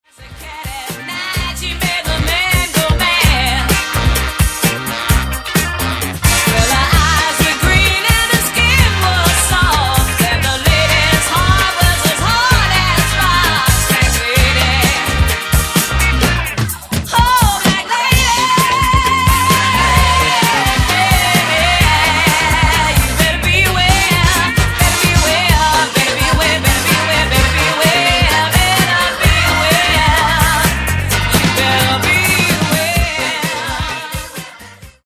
Remastered High Definition
Genere:   Disco